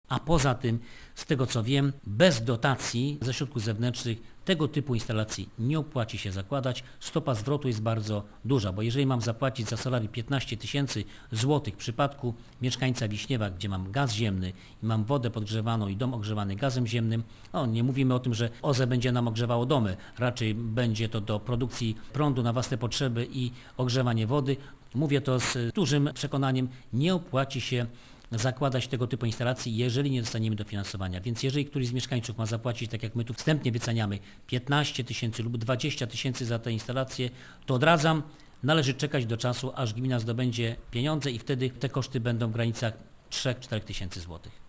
Krzysztof Kryszczuk zastrzega, że projekt będzie realizowany tylko w przypadku otrzymania dotacji, ponieważ samodzielnie gmina nie jest w stanie sfinansować takich inwestycji: